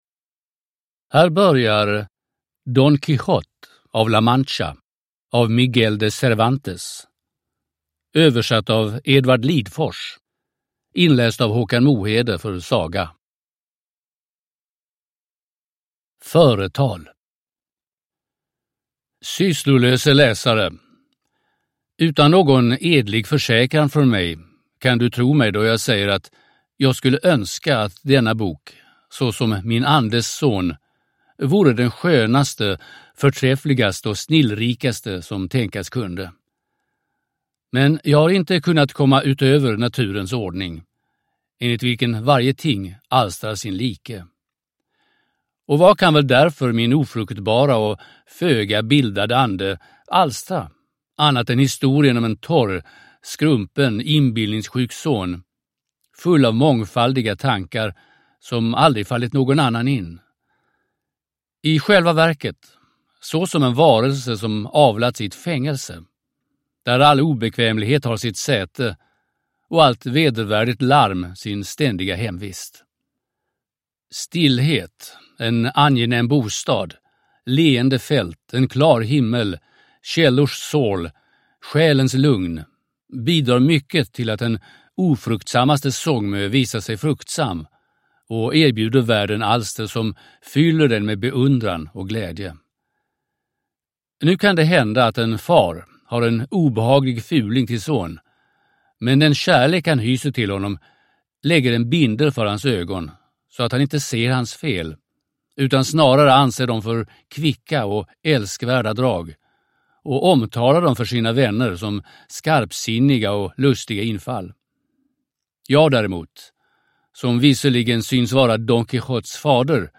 Don Quijote av la Mancha – Ljudbok – Laddas ner